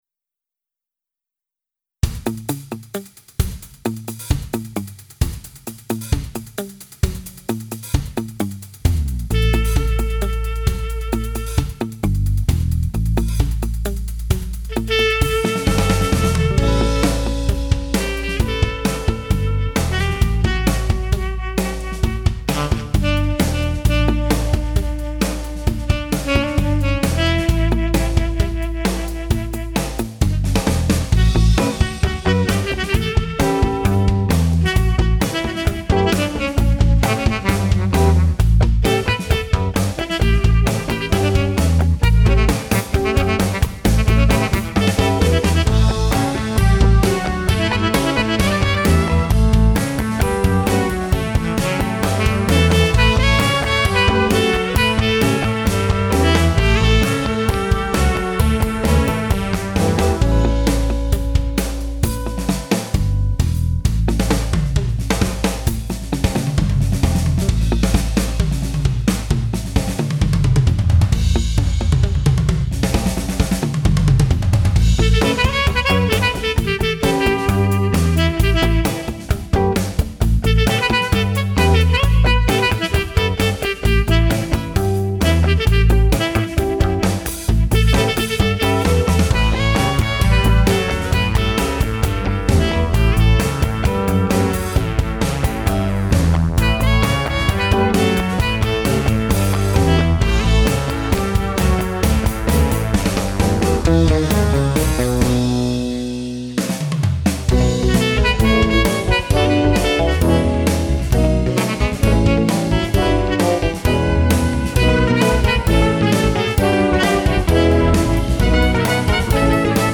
JAZZ ROCK / FUSION / GROOVE